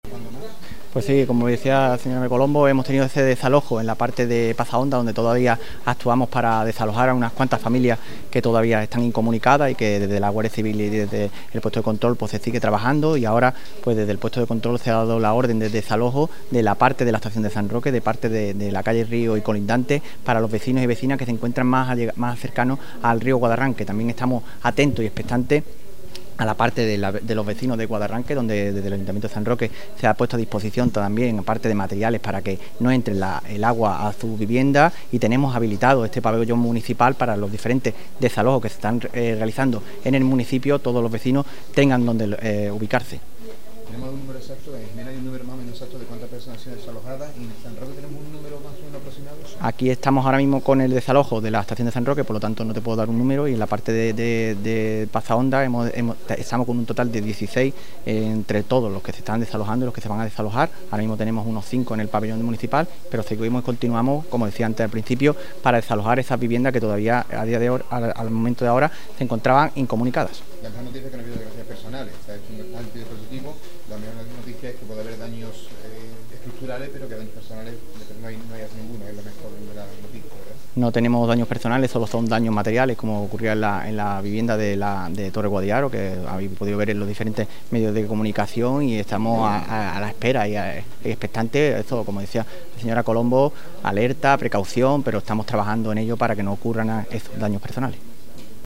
REUNION CECOPAL BORRASCA TOTAL DAVID RAMOS.mp3